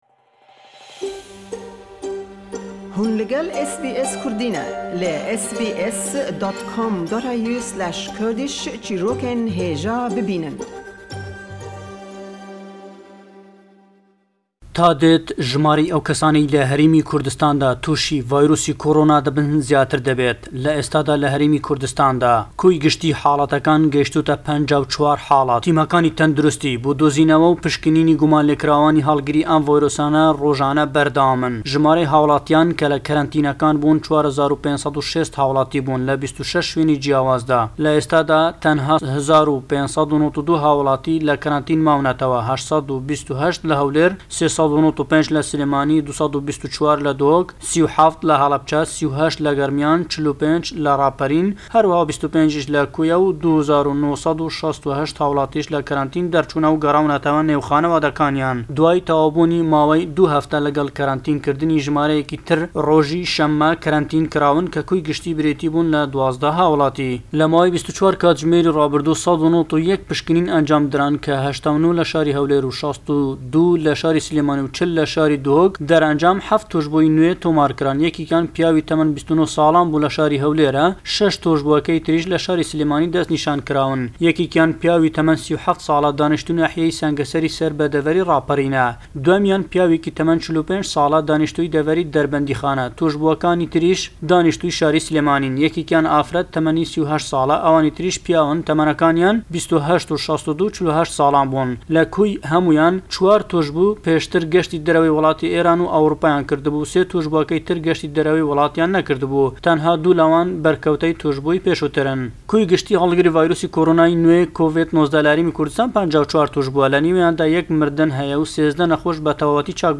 The latest updates from the Kurdish capital, Erbil on the lockdown and quarantine situation due to COVID-19, in this week's report